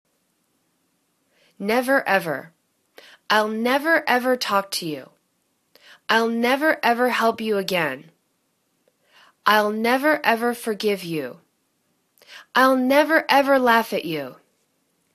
nev.er ev.er     /'nevər 'evər/    adv